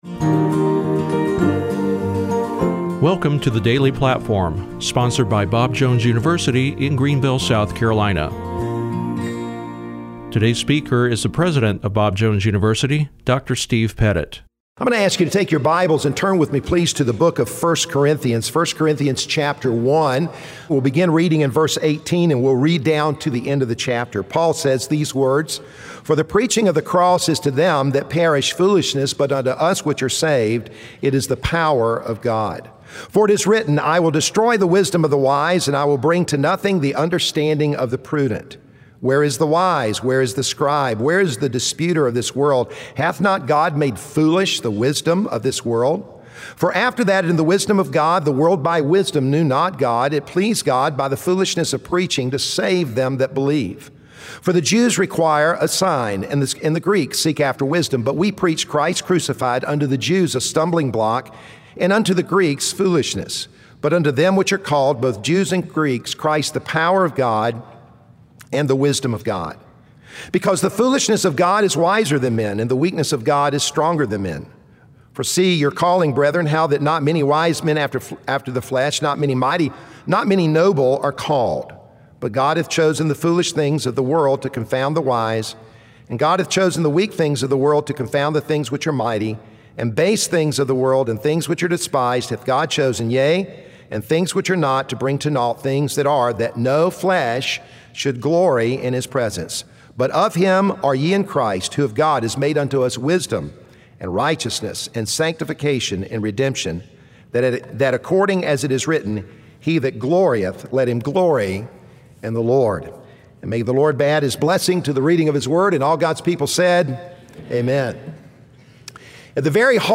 preaches to the student body about the significance of the cross